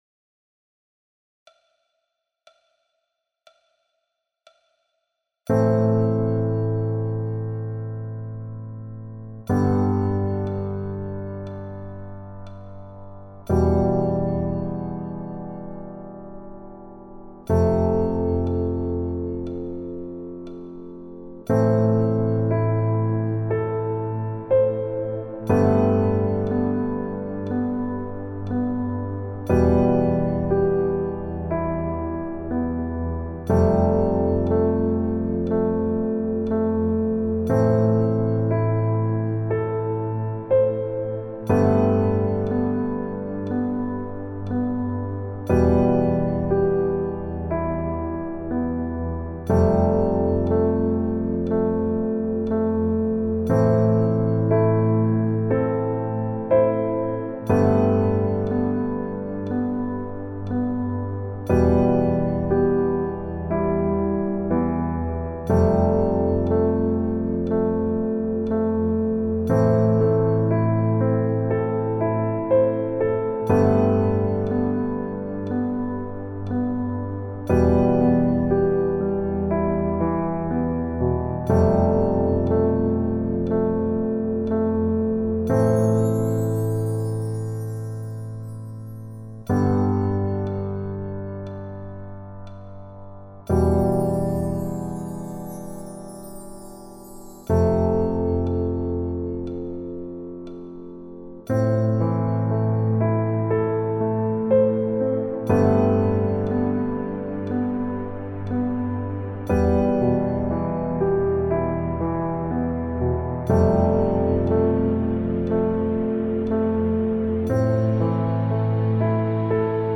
🎵 La musique guide le souffle, sans timer ni voix.